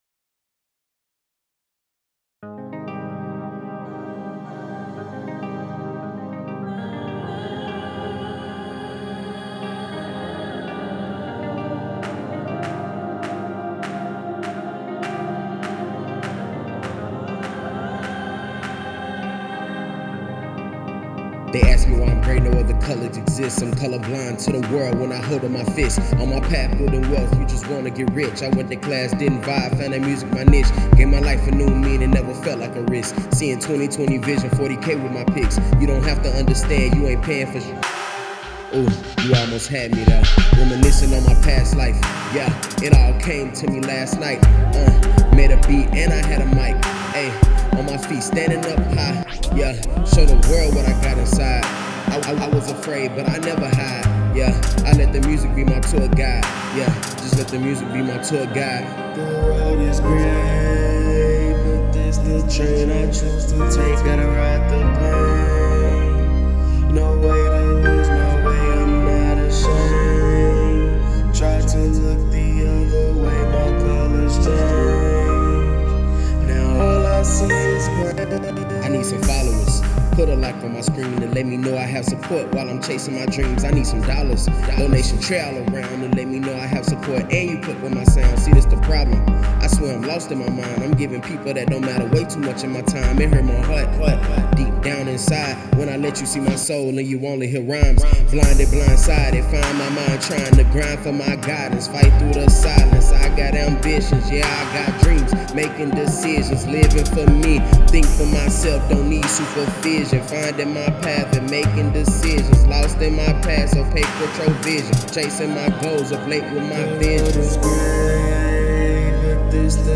Hiphop Music by Gen iY and Z!